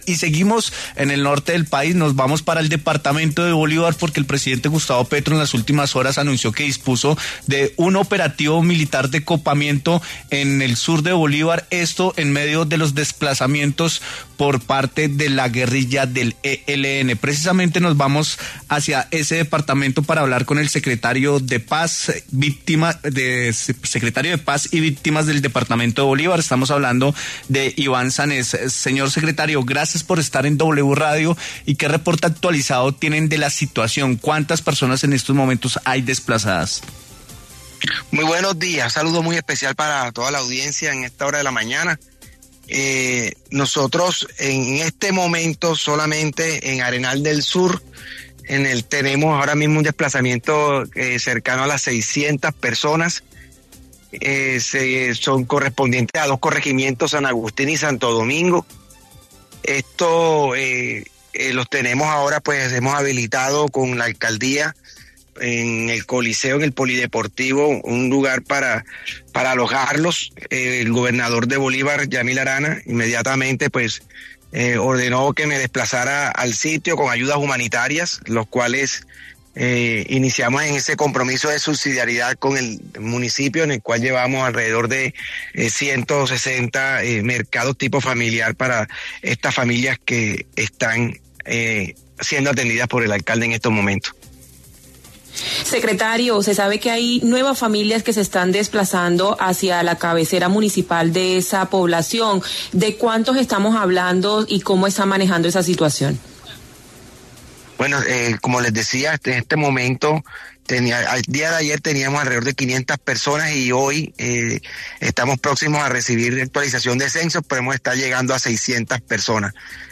En diálogo con W Fin de Semana, el secretario de Paz y Víctimas de Bolívar, Iván Sanes, se pronunció acerca de la crítica situación humanitaria que se registra en el sur del departamento por cuenta de la amenaza de los grupos ELN y el Clan del Golfo que se disputan el control territorial.